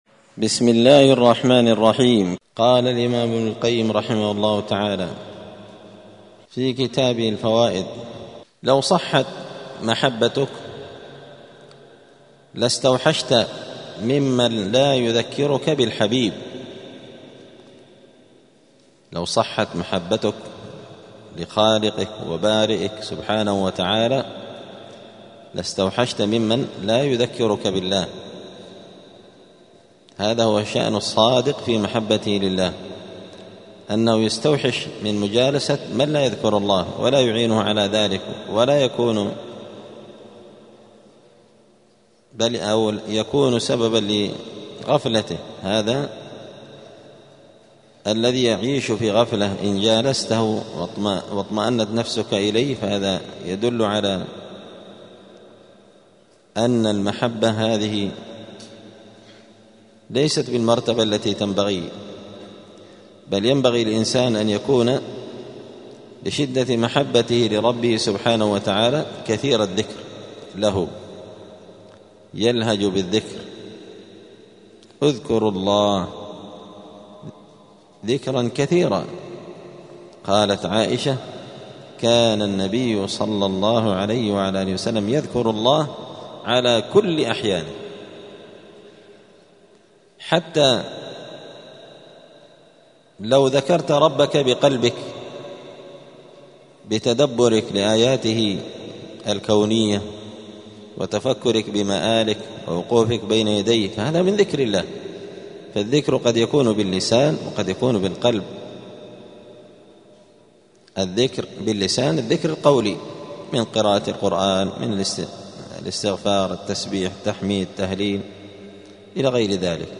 *الدرس الثاني والأربعون (42) {فصل: لو صحت محبتك لله لاستوحشت ممن لا يذكرك بالله}*